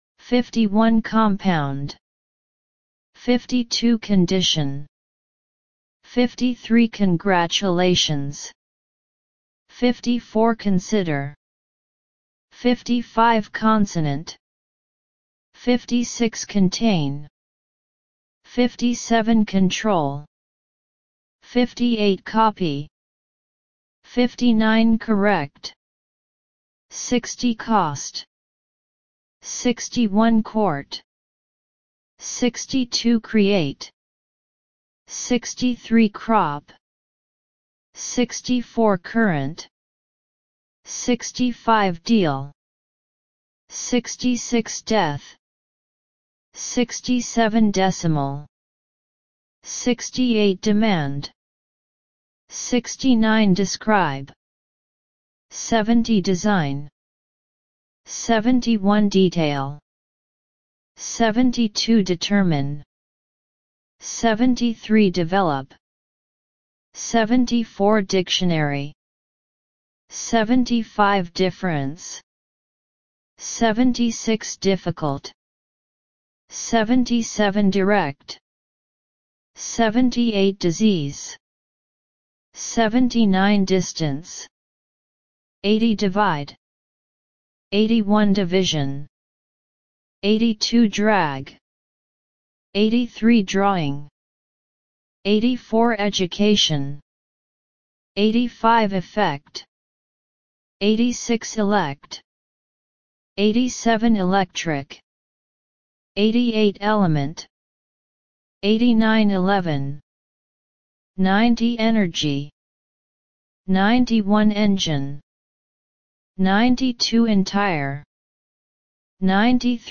51 – 100 Listen and Repeat